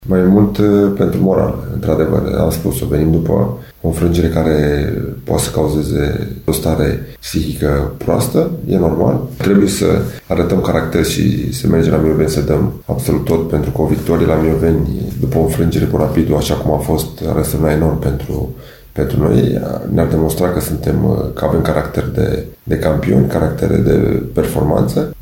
O victorie în următorul meci pentru ASU Politehnica Timișoara ar fi mai importantă pe plan moral, este de părere antrenorul alb-violeților, Dan Alexa. Tehnicianul bănățeană spune că obținerea tuturor punctelor la Mioveni, după eșecul neașteptat cu Rapidul ar ține echipa în obiectivul accesului spre barajul de promovare nicidecum spre vizarea locurilor ce duc direct spre primul eșalon: